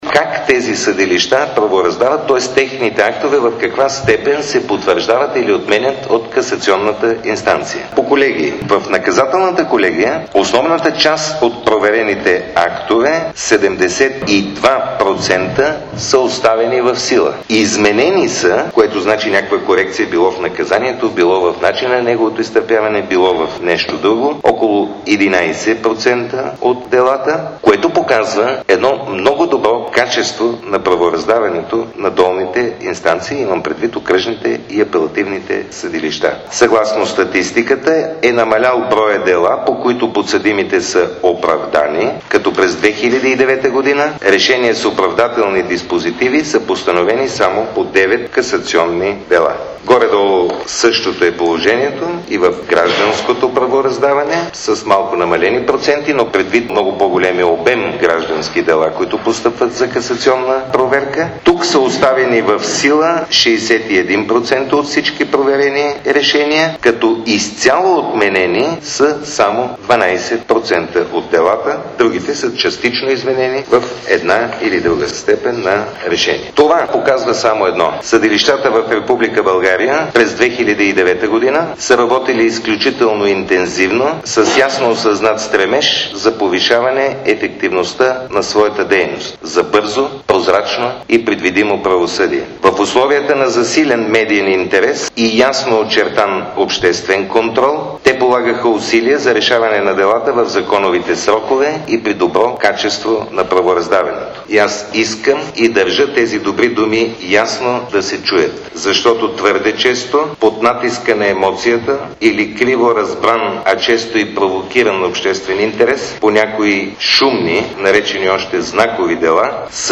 Пресконференция